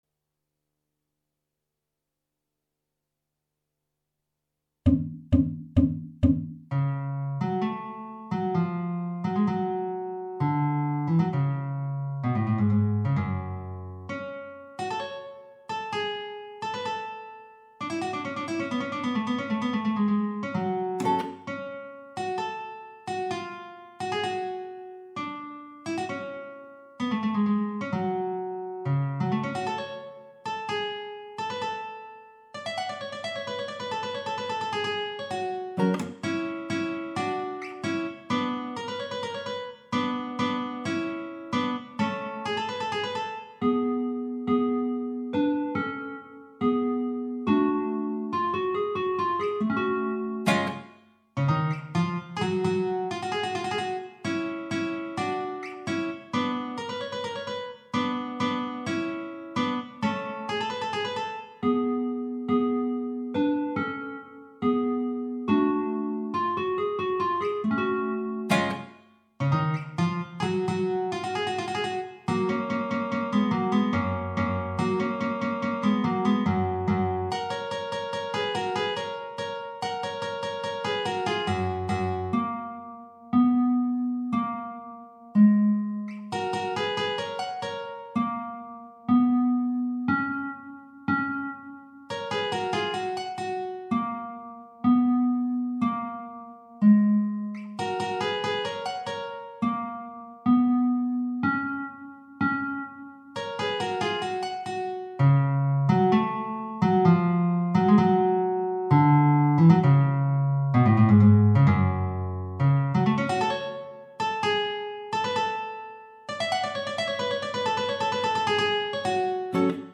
minus Guitar 2